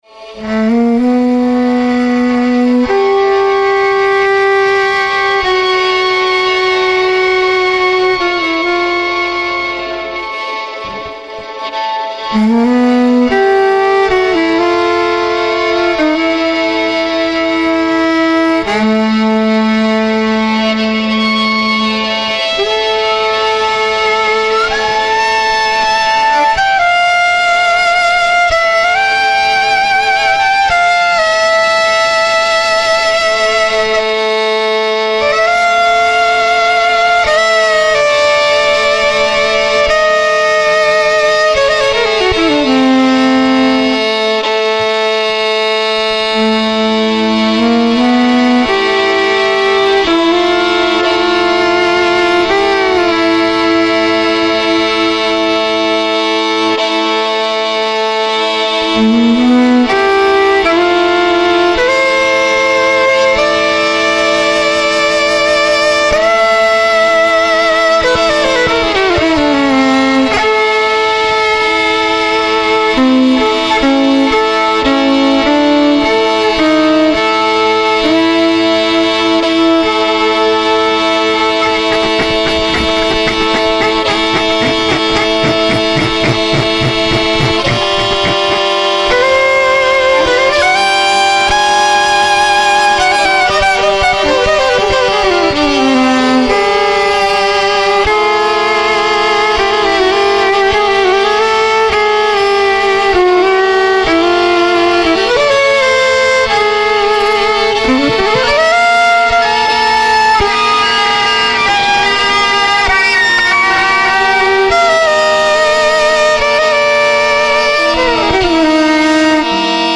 violin, synthesizer
violin
All the tracks are free improvisations
Recorded live, without overdubbing